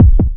bomb_splitter.wav